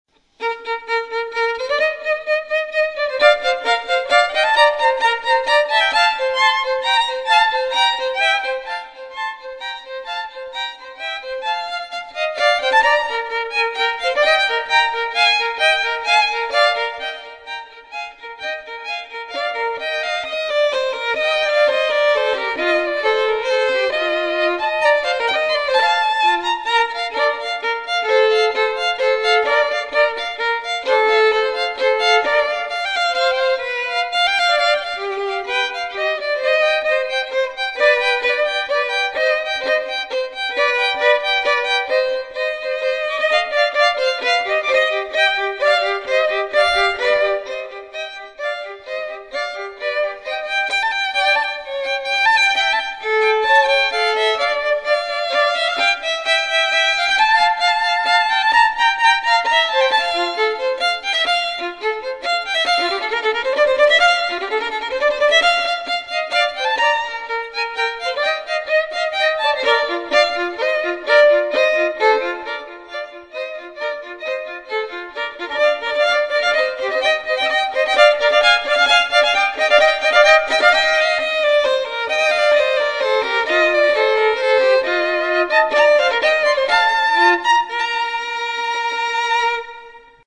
Two Violins
TwoViolins_TelemannDuetBb_Allegro.mp3